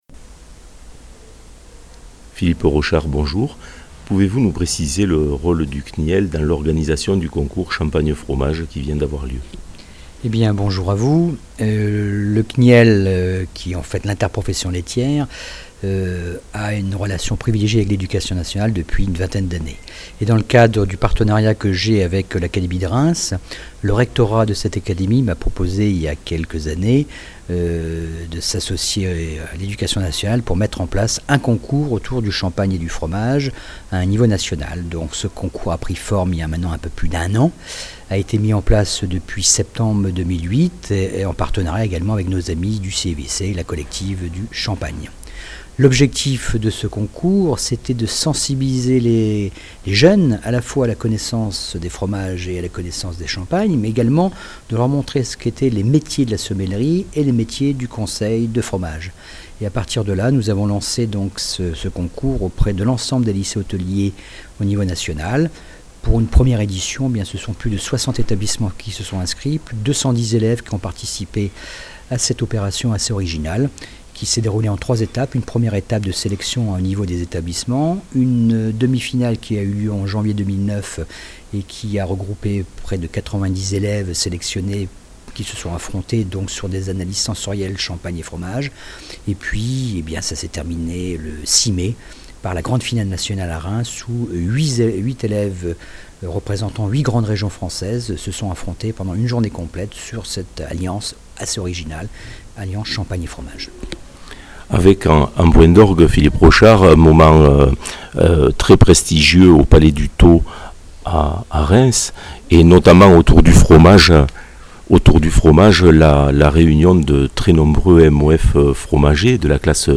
Paroles d’experts